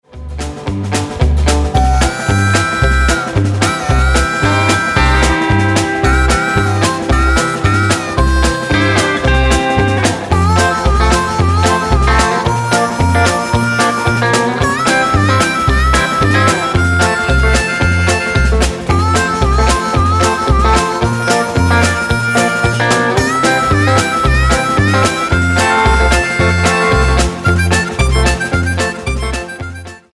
Tonart:C ohne Chor